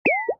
Бип